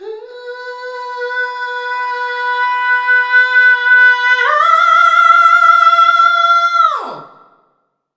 SCREAM 3  -L.wav